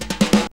JAZZ FILL 9.wav